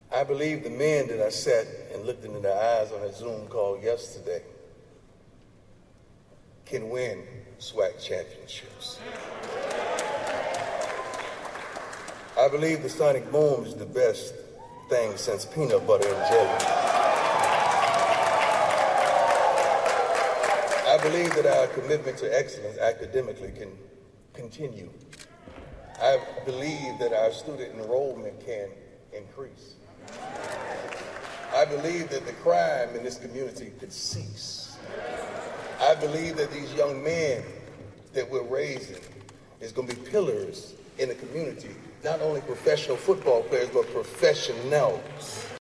The new head coach was overcome with emotion in his introductory press conference on Monday.